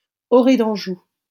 -Orée-d’Anjou.wav Audio pronunciation file from the Lingua Libre project.